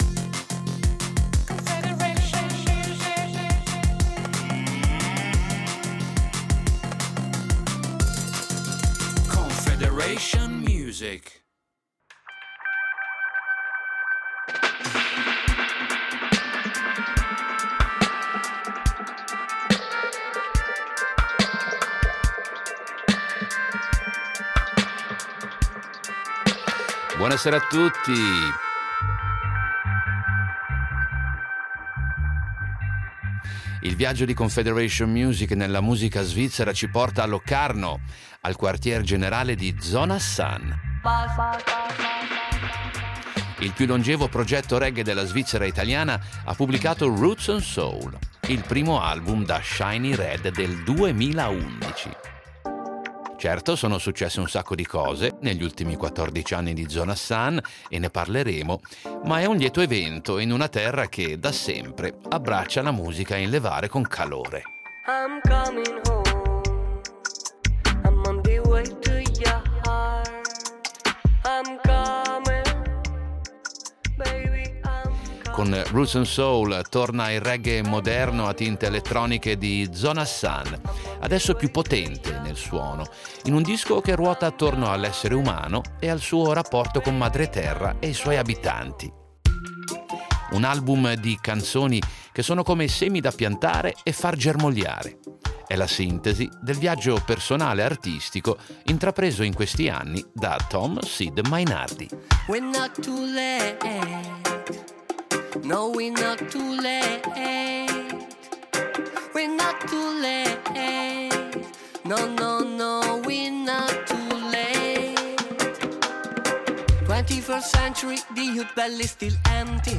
Musica reggae